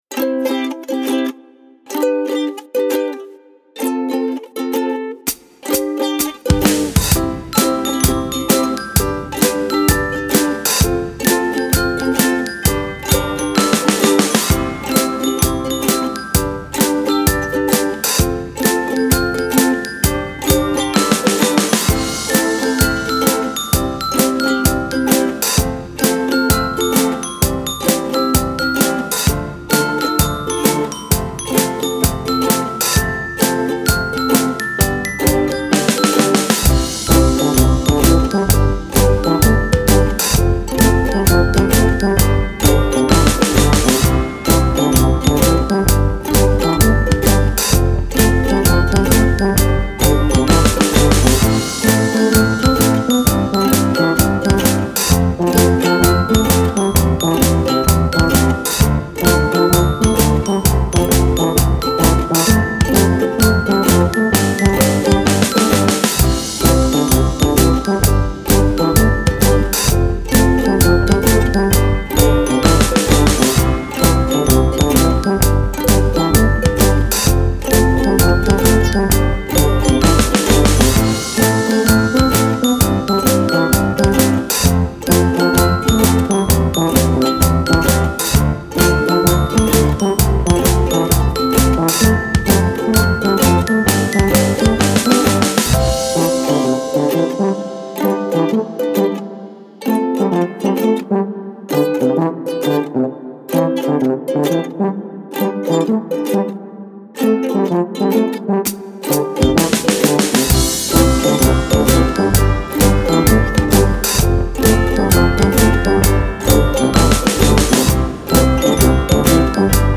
Style Style Folk, Pop
Mood Mood Bouncy, Bright, Uplifting
Featured Featured Bass, Bells, Brass +3 more
BPM BPM 130
Positive sounding background music.